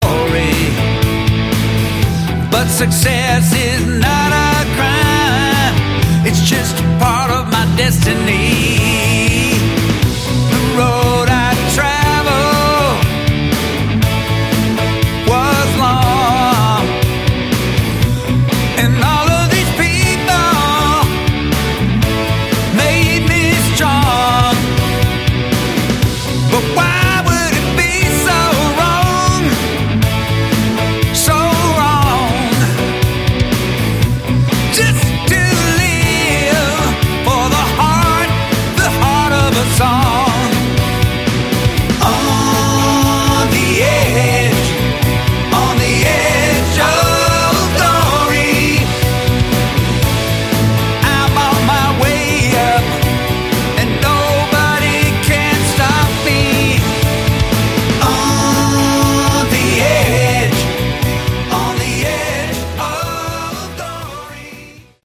Melodic Rock